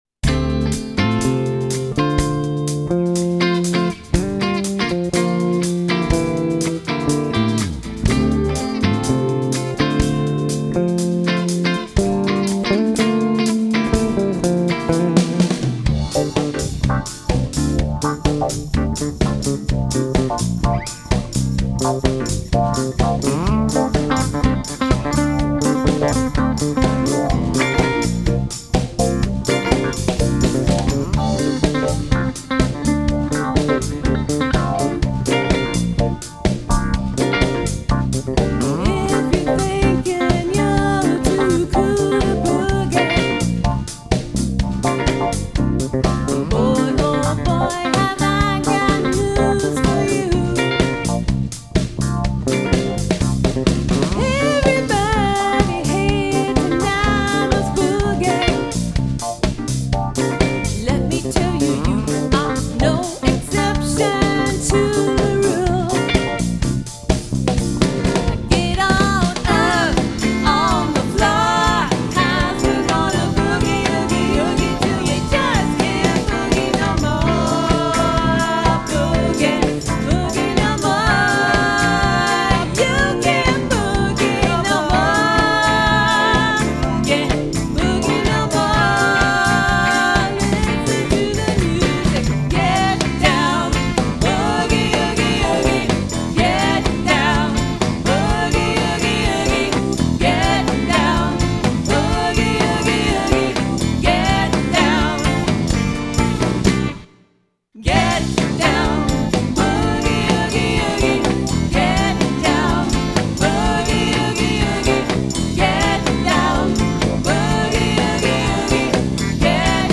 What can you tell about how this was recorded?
Recorded at Polar Productions Studio, Pismo Beach, CA